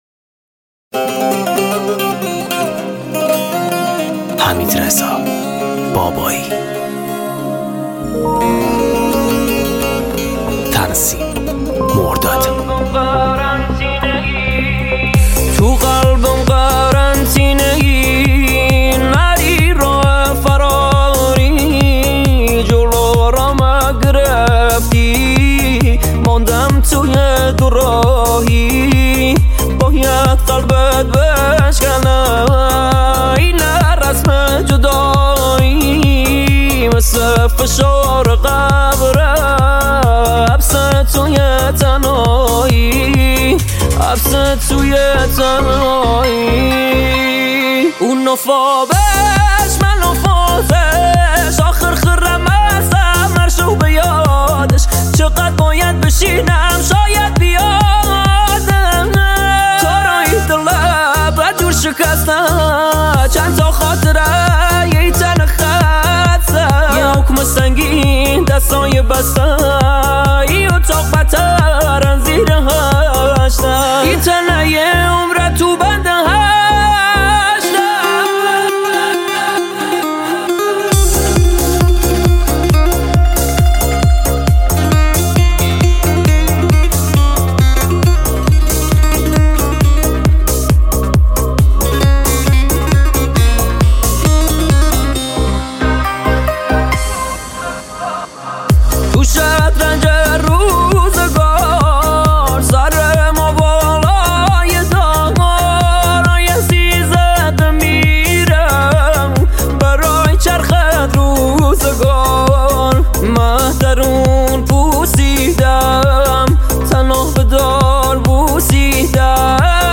پاپ محلی غمگین عاشقانه کردی عاشقانه غمگین